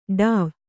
♪ ḍāv